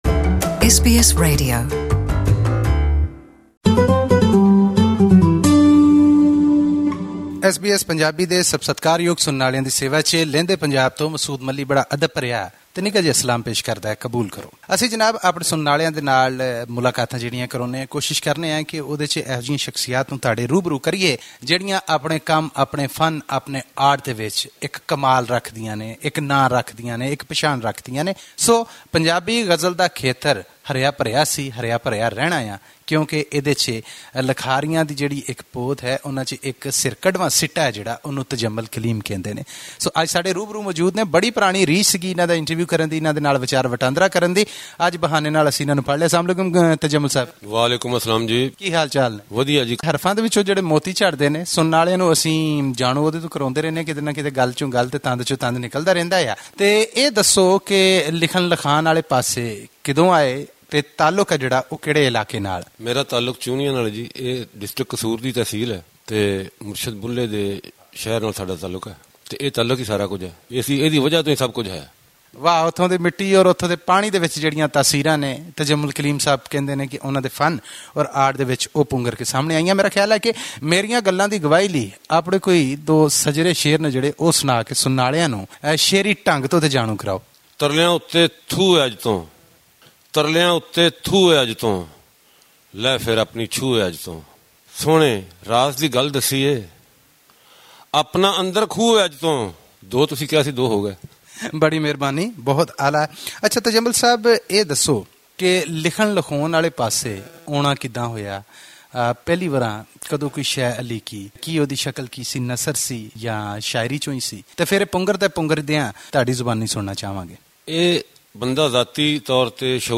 Listen to this interview in Punjabi by clicking on the player at the top of the page.